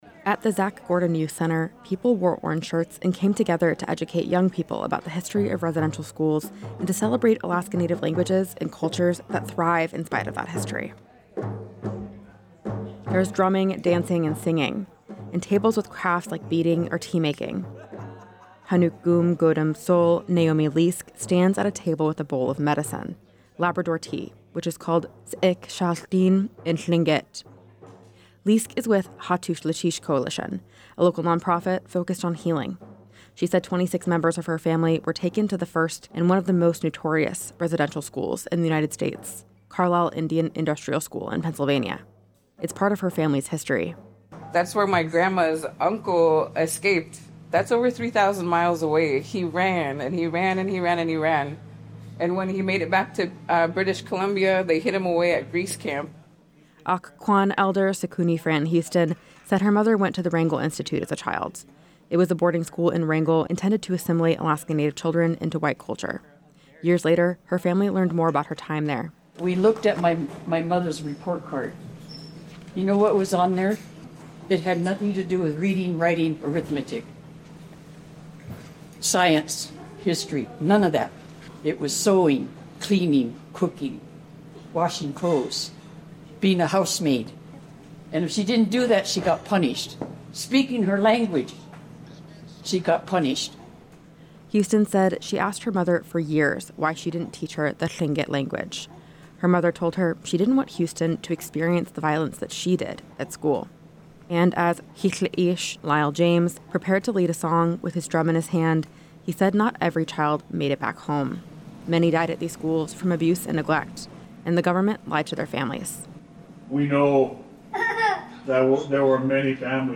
At the Zach Gordon Youth Center, people wore orange shirts and came together to educate young people about the history of residential schools and to celebrate Native languages and cultures that thrive in spite of that history.
There was drumming, singing and dancing, and tables with crafts like beading or tea-making.